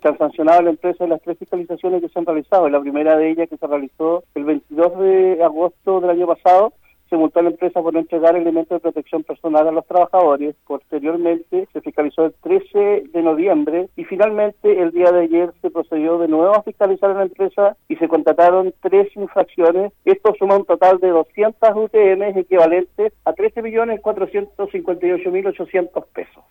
En conversación con Radio Bío Bío, el director regional del Trabajo en Los Ríos, César Paredes, confirmó que la empresa que actualmente tiene a cargo el servicio de cobro de los estacionamientos en La Unión, ha sido inspeccionada en tres ocasiones en los últimos seis meses.
El director Cesar Paredes indicó que la empresa deberá pagar más de $13 millones por los incumplimientos antes mencionados.